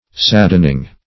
saddening - definition of saddening - synonyms, pronunciation, spelling from Free Dictionary